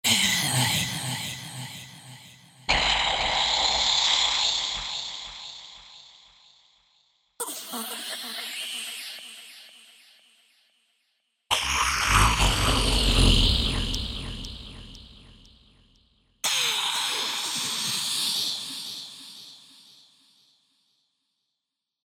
Vocal Effects
Breathy bietet auch monster- oder hexentaugliche Stimmen, vor allem, wenn man die Samples mittels Pitch-Regler transponiert.